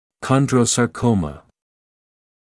[ˌkɒndrə(u)ˌsɑː’kəumə][ˌкондро(у)ˌcаː’коумэ]хондросаркома